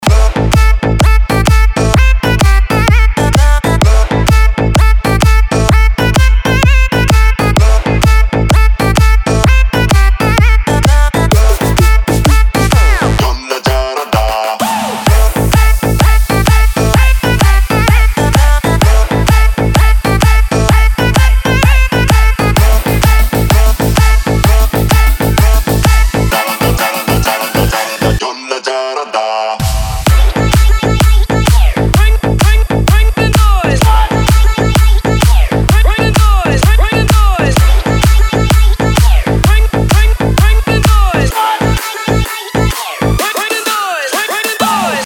из Клубные
Категория - клубные.